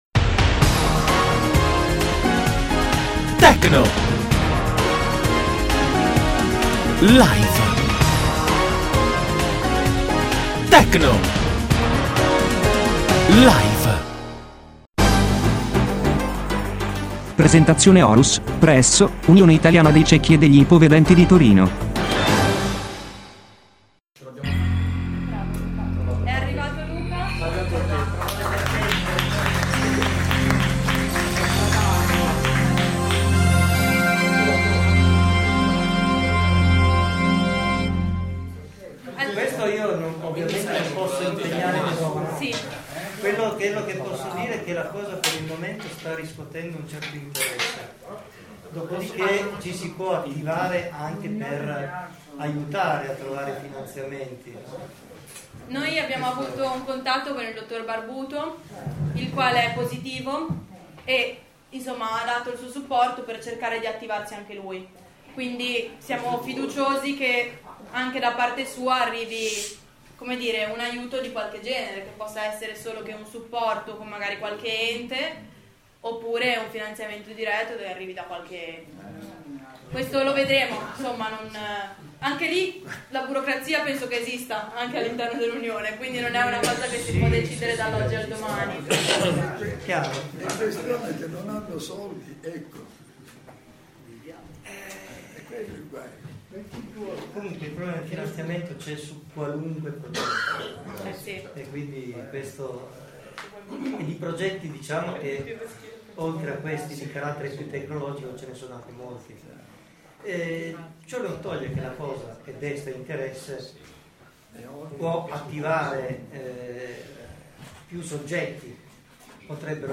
Si tratta della registrazione della seconda parte dell'incontro tenutosi presso la sezione di Torino dell'Unione Italiana Ciechi e degli Ipovedenti, nel quale vengono illustrate le potenzialità della tecnologia Horus.